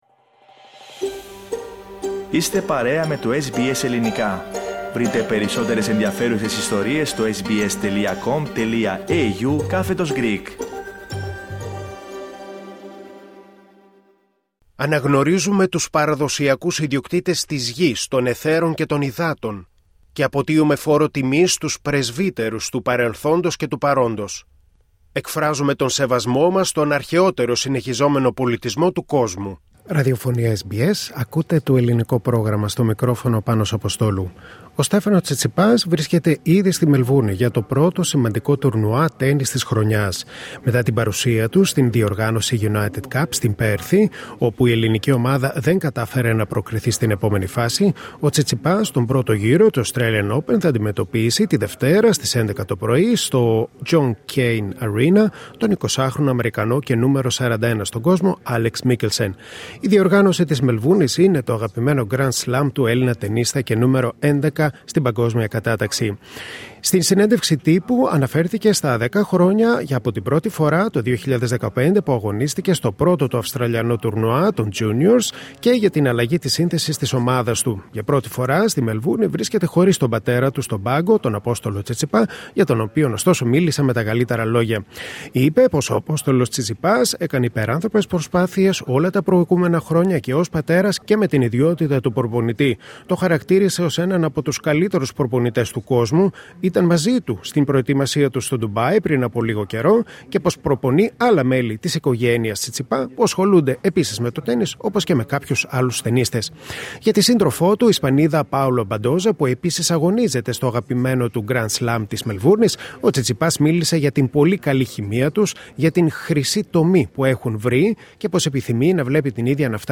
Ο κορυφαίος Έλληνας τεννίστας, Στέφανος Τσιτσιπάς βρίσκεται ήδη στη Μελβούρνη για το πρώτο σημαντικό τουρνουά τέννις της χρονιάς και απάντησε σε ερωτήσεις του SBS Greek